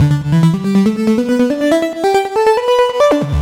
Index of /musicradar/french-house-chillout-samples/140bpm/Instruments
FHC_Arp B_140-E.wav